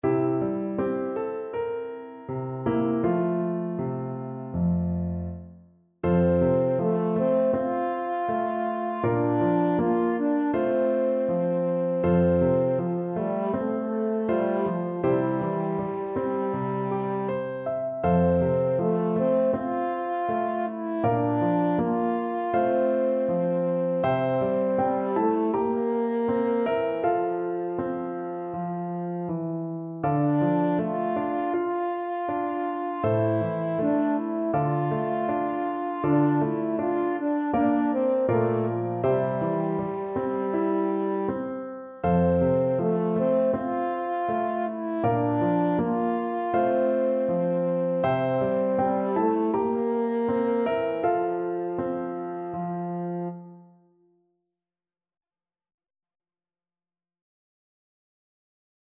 French Horn
4/4 (View more 4/4 Music)
E4-F5
F major (Sounding Pitch) C major (French Horn in F) (View more F major Music for French Horn )
Andante
Classical (View more Classical French Horn Music)